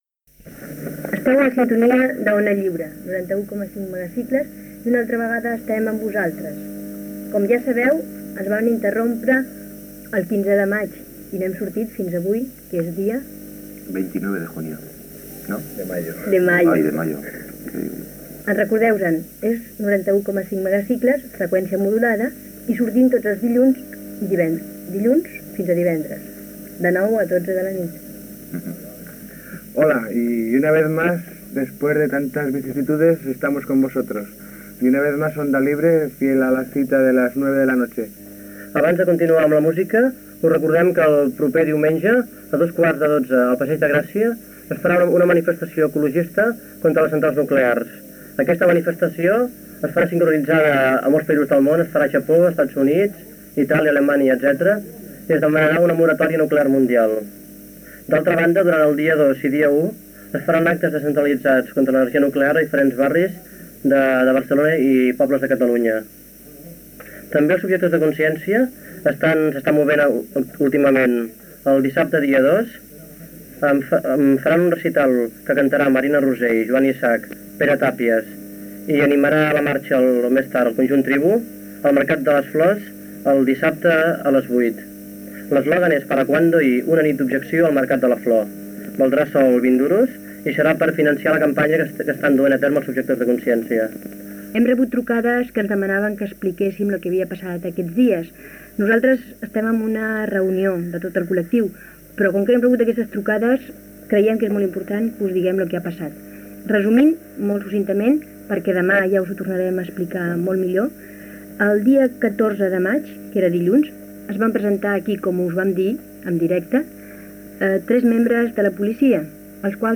304947d326d24a65350d97f12b5ccfc6e05d7235.mp3 Títol Ona Lliure Emissora Ona Lliure Titularitat Tercer sector Tercer sector Lliure Descripció Manifestació ecologista, acte dels objectors de consciència i comentari després del segon tancament i precintat del local, el 15 de maig de 1979. Gènere radiofònic Informatiu